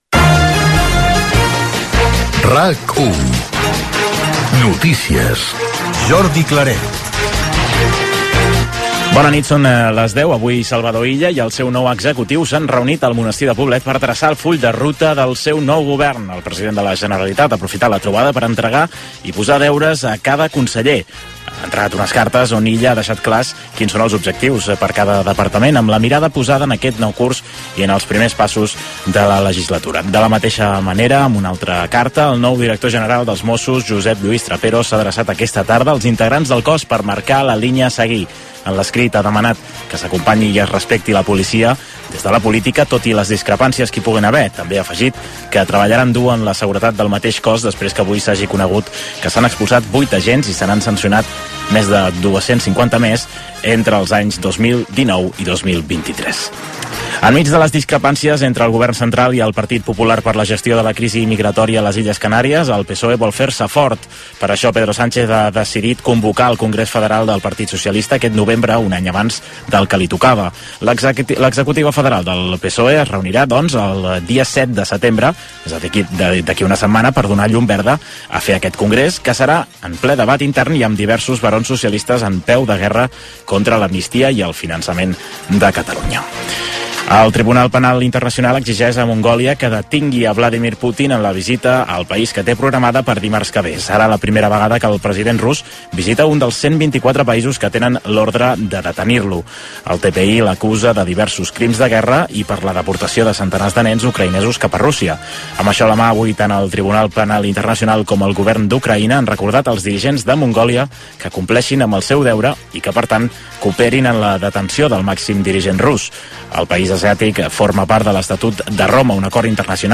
Careta del programa, reunió del govern de la Generalitat de Salvador Illa al monestir de Poblet, carta del Major Trapero als Mossos d'Esquadra, crisis migratòria a les Illes Canàries, Mongòlia, robatori de calble de coure a rodalies, esports, el temps, careta, indicatiu d'estiu de la ràdio
Informatiu